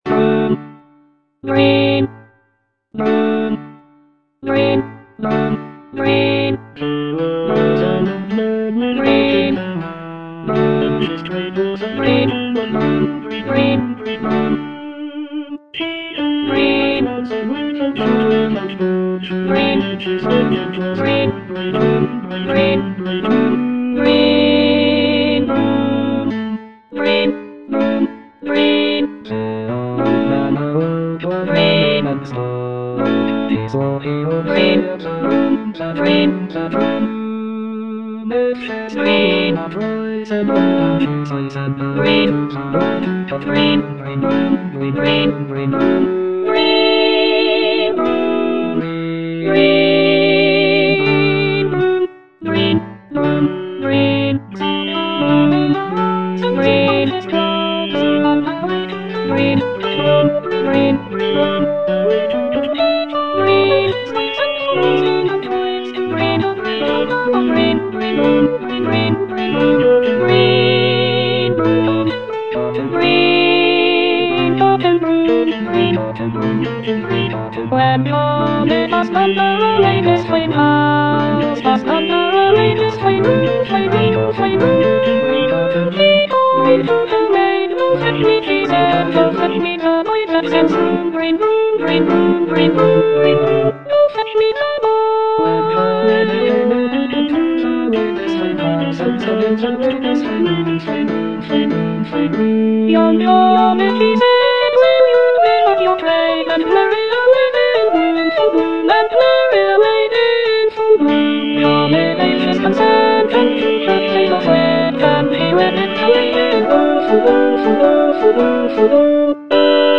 Alto II (Emphasised voice and other voices)
for voice and piano